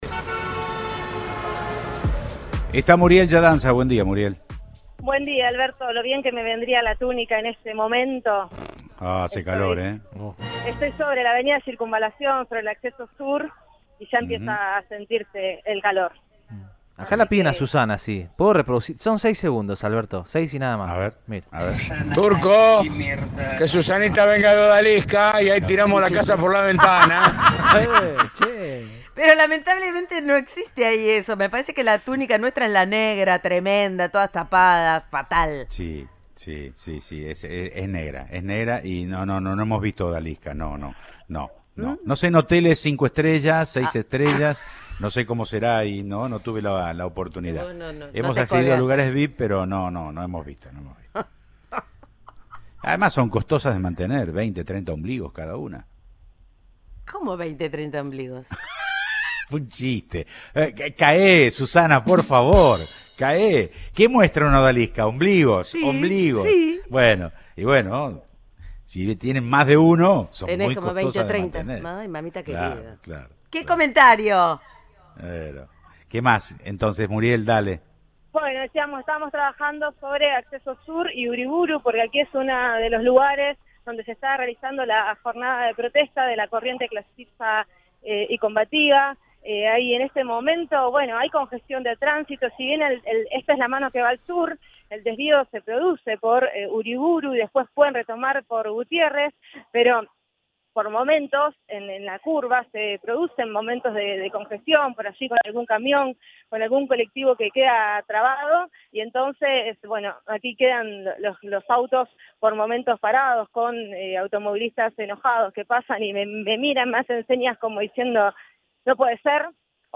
una de las manifestantes, habló con el móvil de Cadena 3 Rosario